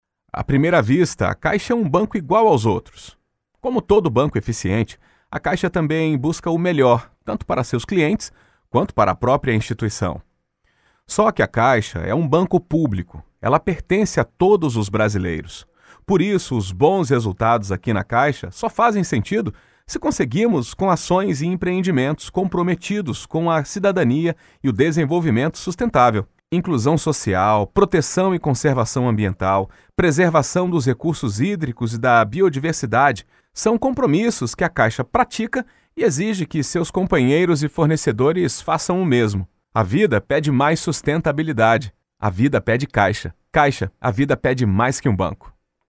A natural and versatile voice without vices or exaggerations, neutral accent to perform many different styles characters and ages, always giving the right approach to engage the audience.
Sprechprobe: Industrie (Muttersprache):